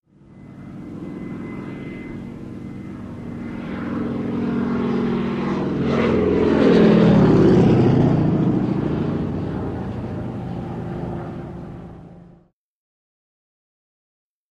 Japanese Zero|Bys
Airplane Japanese Zero By Right To Left Medium Speed Medium Perspective